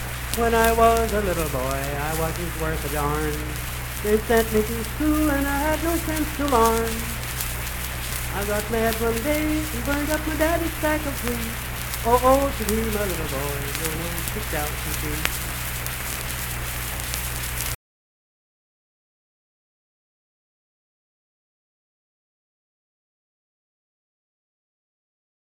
Unaccompanied vocal music
Verse-refrain 1(8).
Voice (sung)